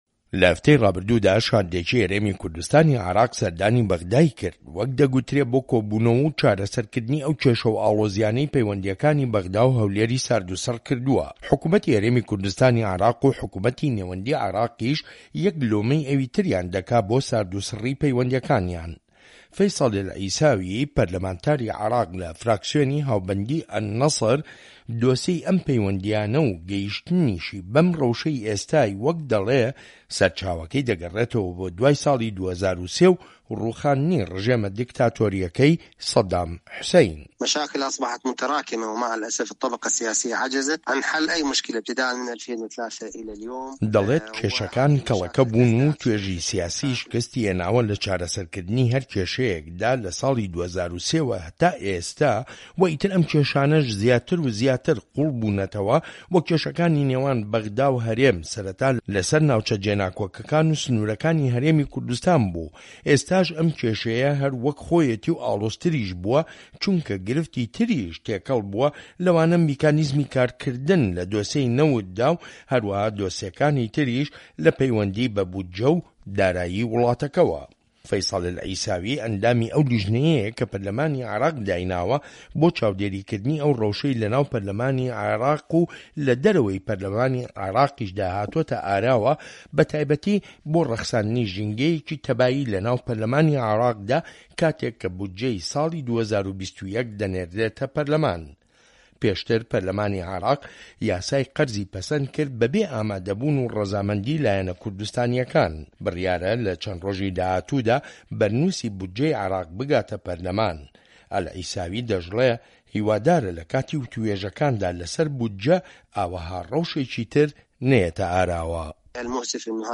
ڕاپۆرت لەسەر بنچینەی لێدوانەکانی فەیسەڵ ئەلعیساوی